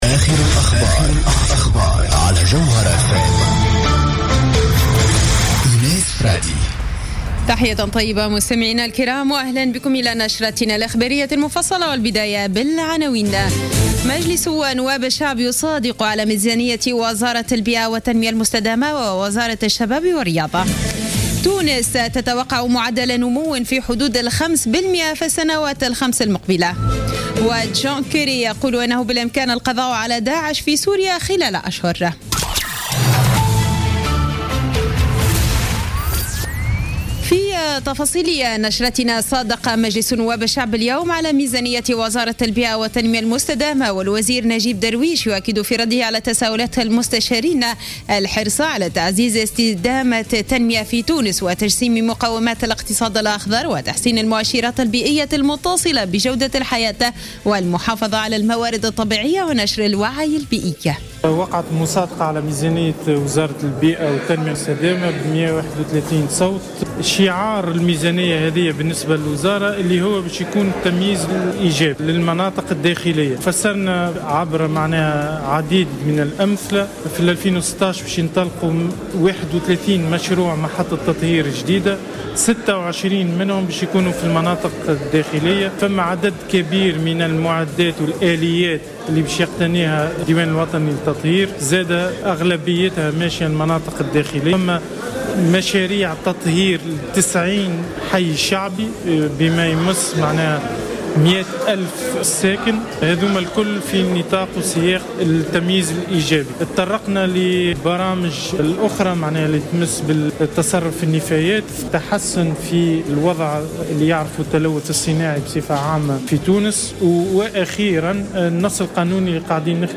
نشرة أخبار السابعة مساء ليوم الخميس 3 ديسمبر 2015